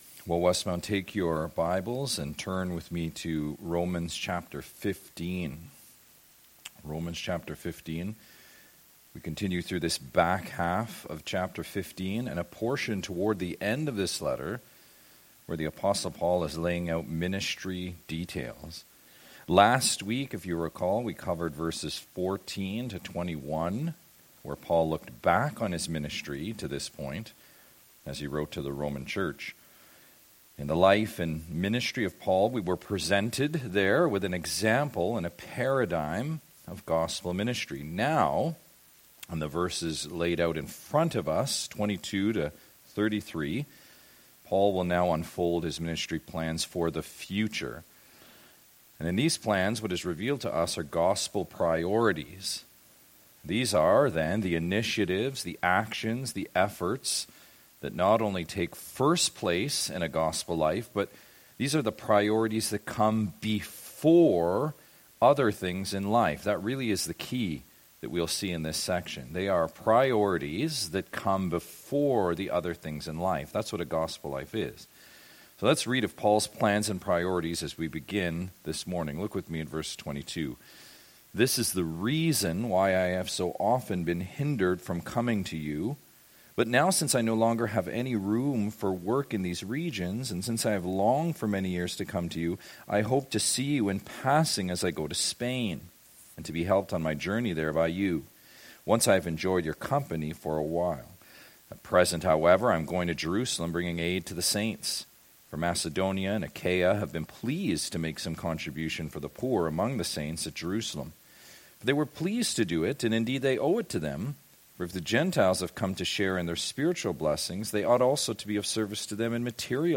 Westmount Bible Chapel, a Bible based, Christ-centred, Christian Bible church in Peterborough, Ontario committed to bringing truth by expository preaching.